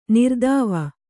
♪ nirdāva